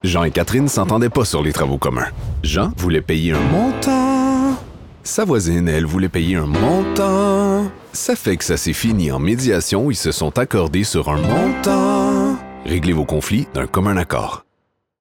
Voix annonceur – IMAQ
claire, articulée, chantée